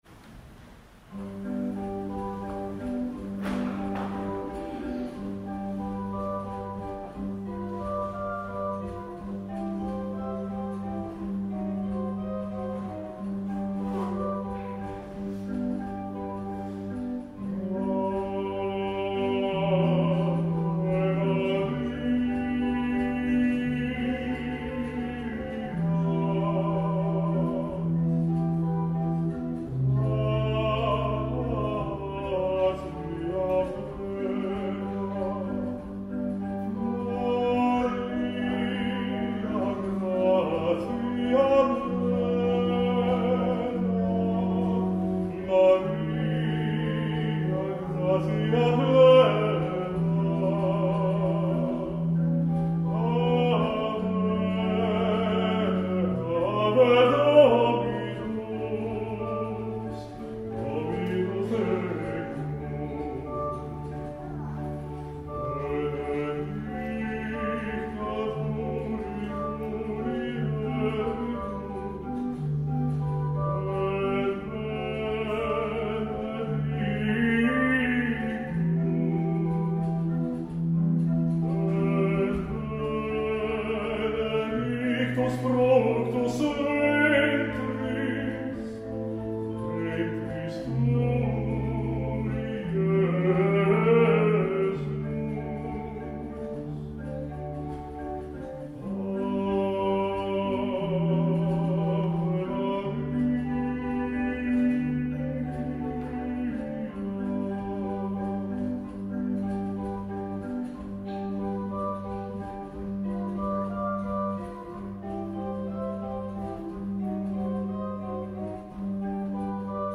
声楽・アンサンブルのオススメ：
アヴェマリア（シューベルト）：穏やかで心休まる厳かさ満載。規則的な３度移動が心地よさを際立たせる名曲